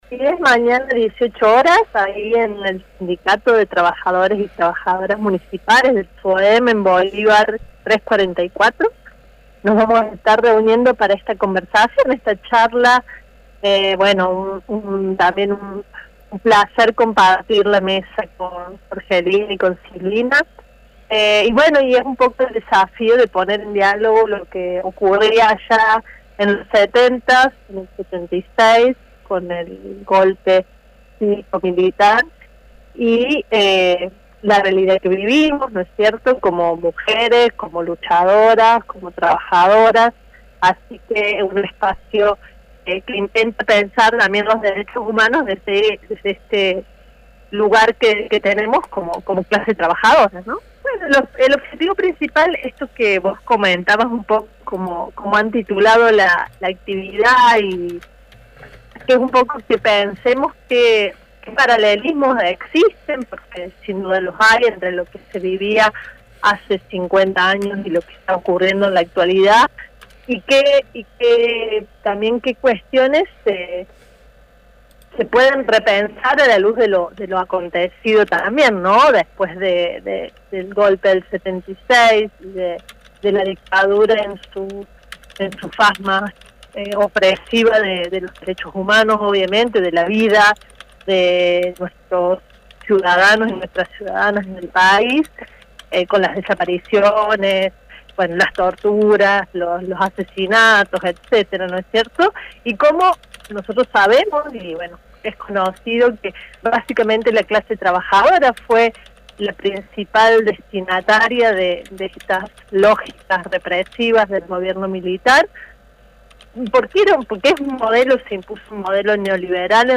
En diálogo con Universidad, dijo que las clases trabajadoras están en situación empobrecida.